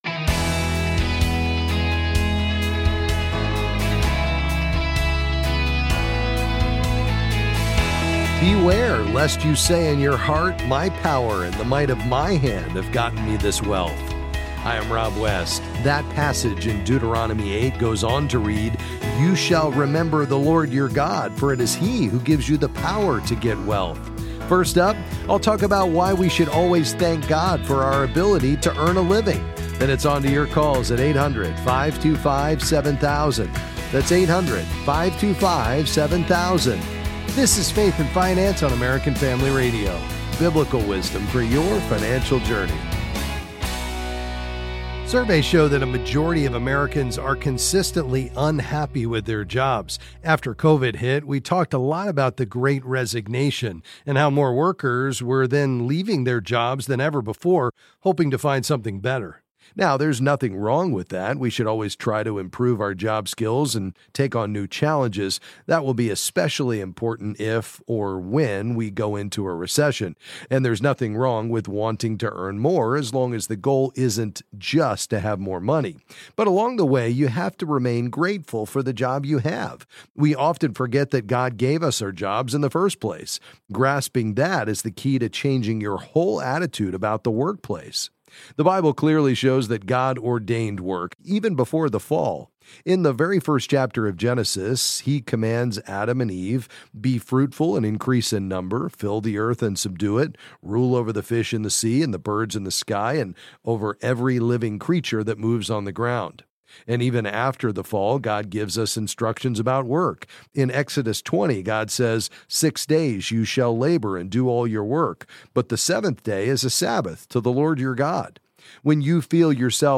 Then he’ll answer questions on various financial topics.